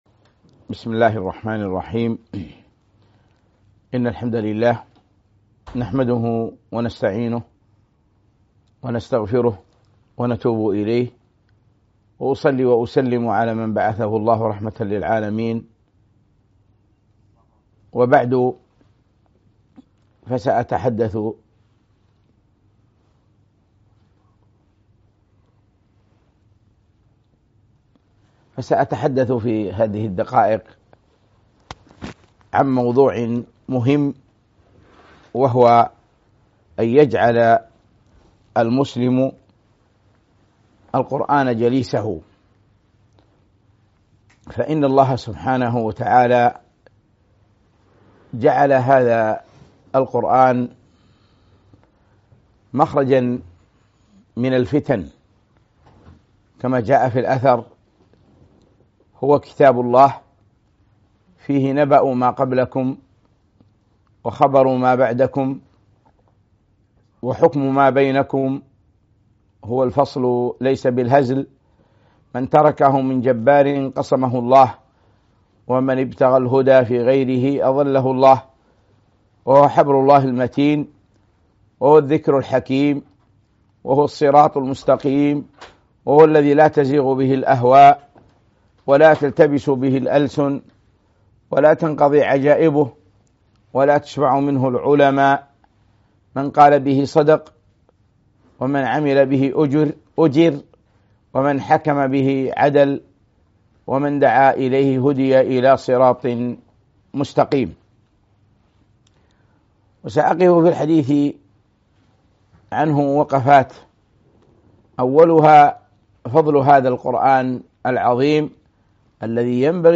محاضرة - اجعل القرآن جليسك